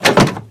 gear_change_01.ogg